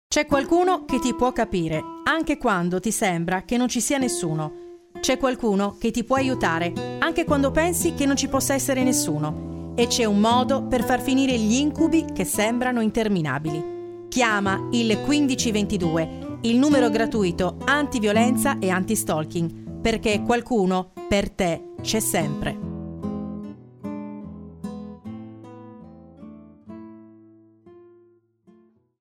Spot campagna sensibilizzazione "Violenza Domestica 2025"